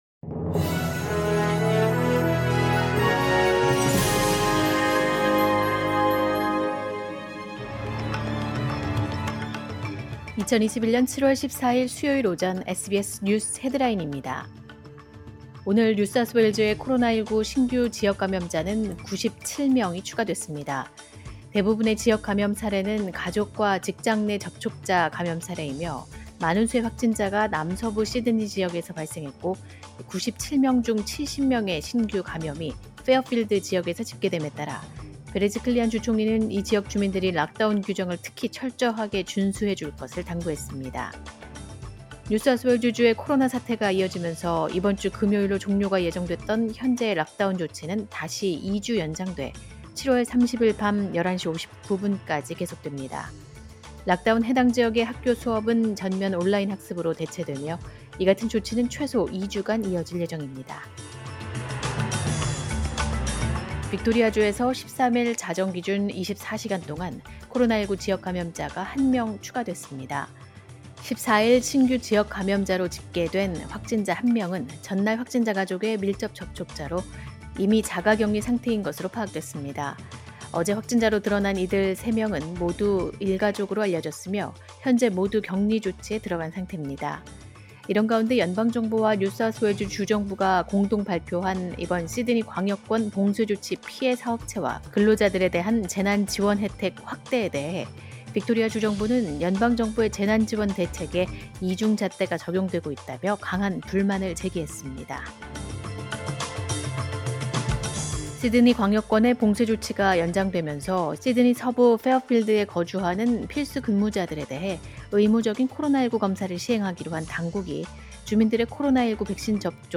2021년 7월 14일 수요일 오전의 SBS 뉴스 헤드라인입니다.